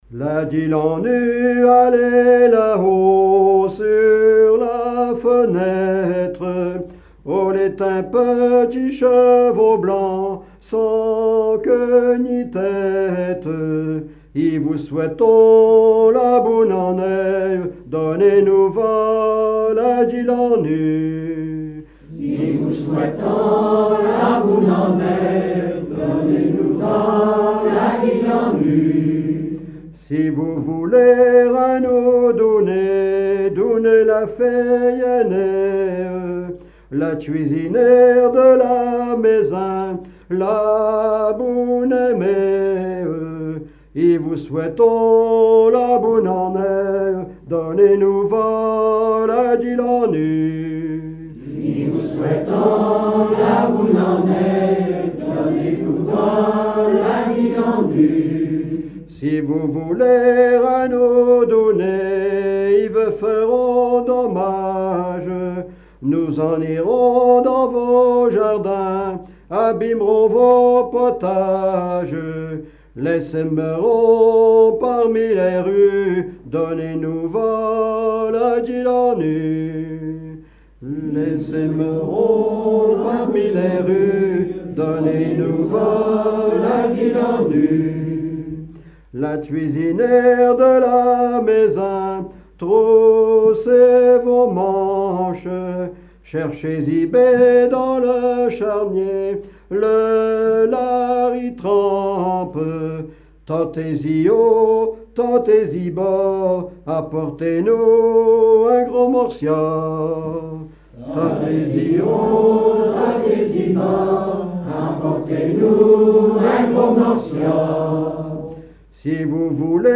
circonstance : quête calendaire
Veillée (version Revox)
Pièce musicale inédite